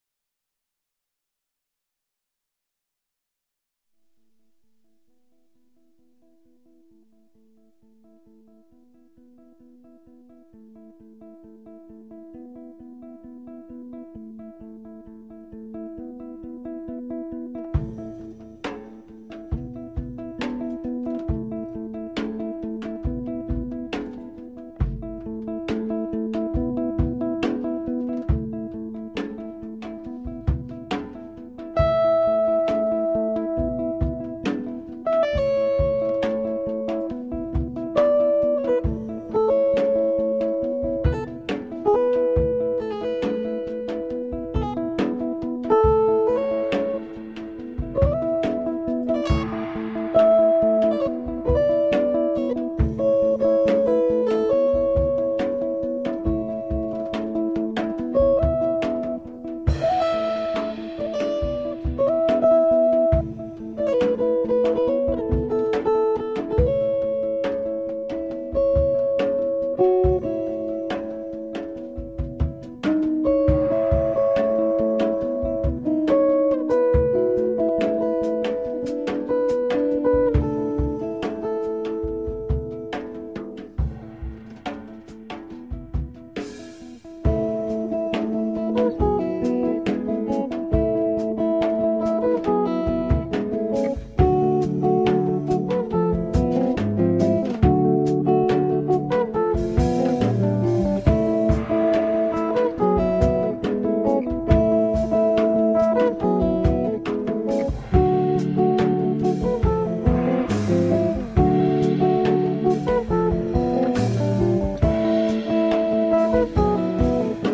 chitarra
basso elettrico e contrabbasso
batteria